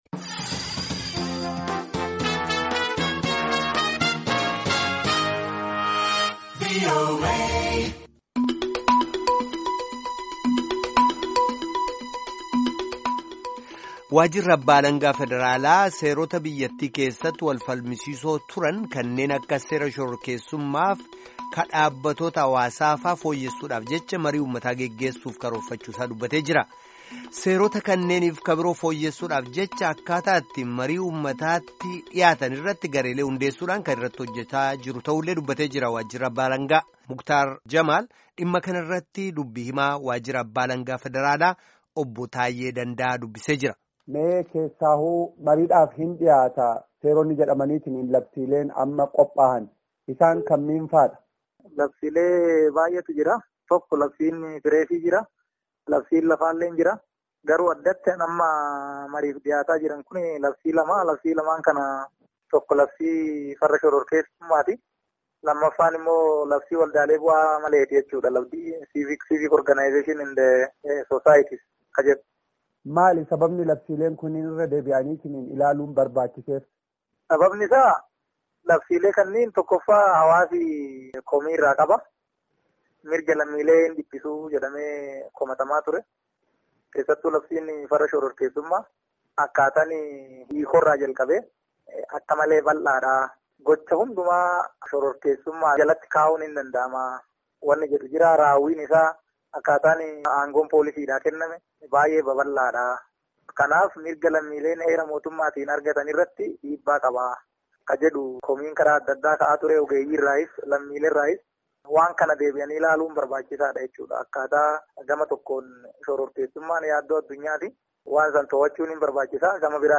Gabaasaan keenya